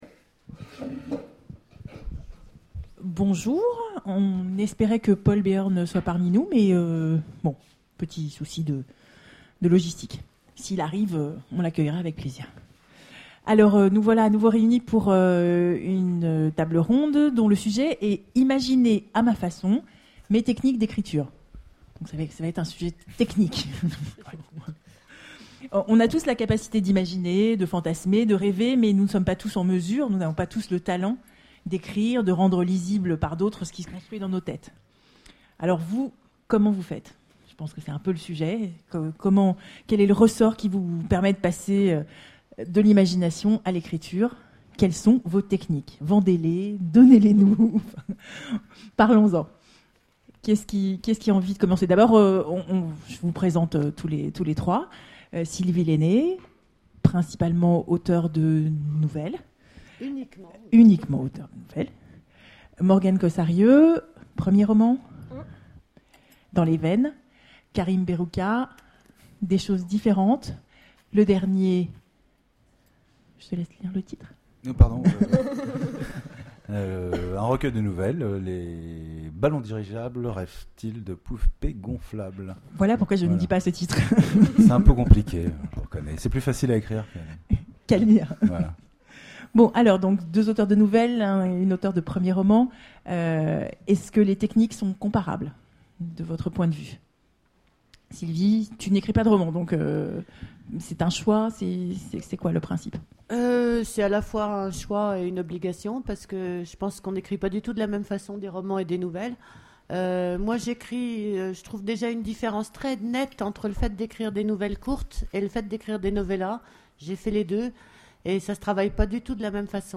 Imaginales 2013 : Conférence Imaginer à ma façon